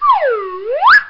Slide Whistle Sound Effect
Download a high-quality slide whistle sound effect.
slide-whistle.mp3